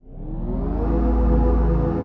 heatshieldson.ogg